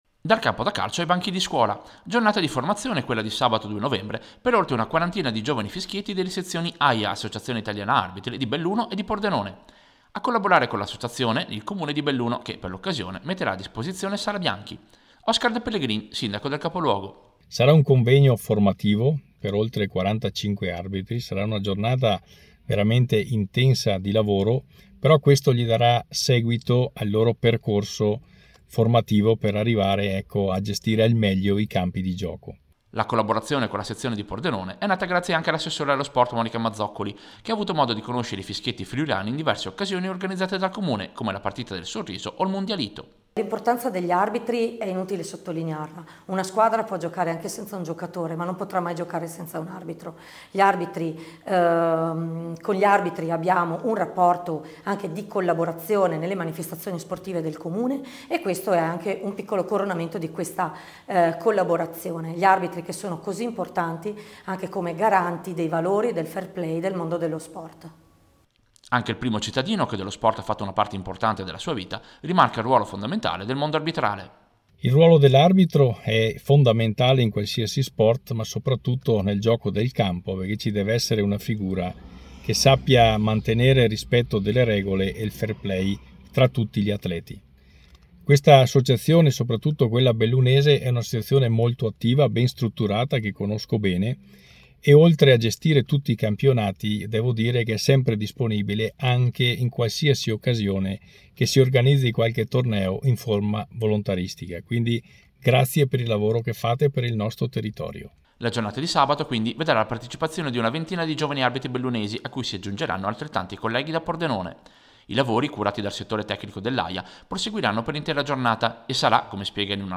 Servizio-Formazione-arbitri-Belluno-Pordenone.mp3